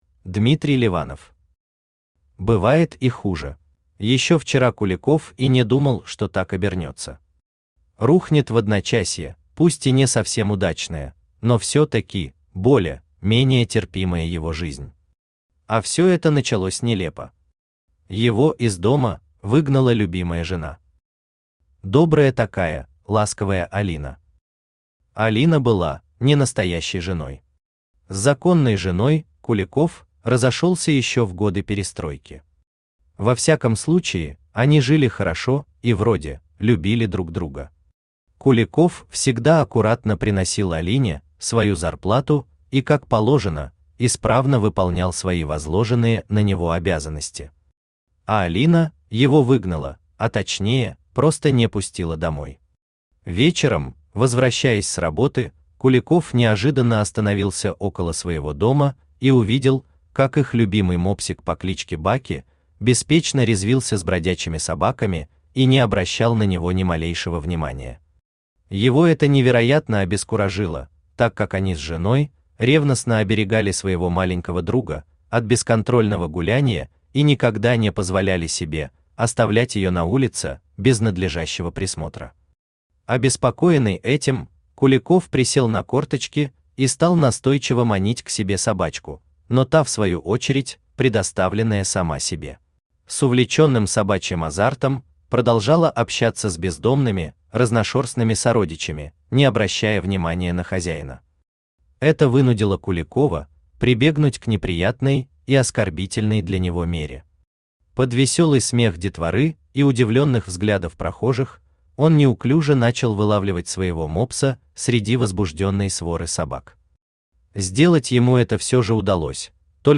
Аудиокнига Бывает и хуже…
Aудиокнига Бывает и хуже… Автор Дмитрий Леванов Читает аудиокнигу Авточтец ЛитРес.